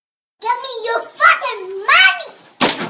gimmeurmoneywiththud.mp3